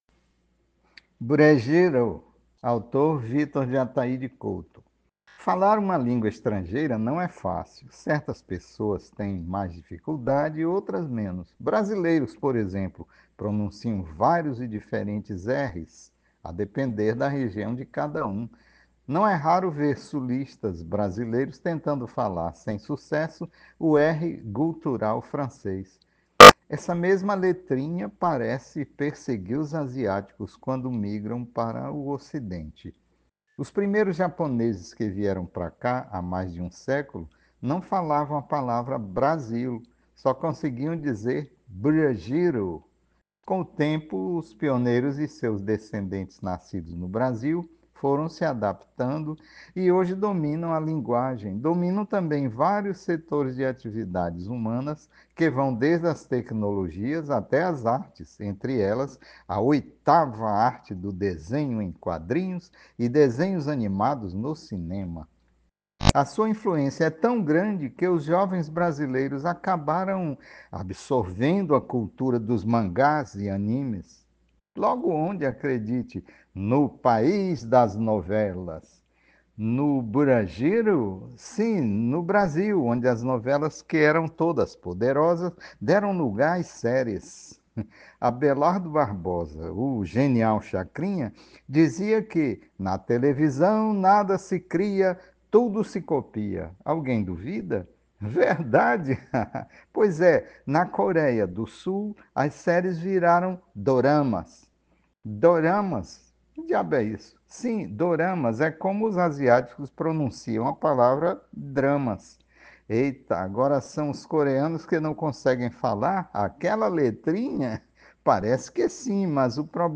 Ouça o áudio com a gravação do autor: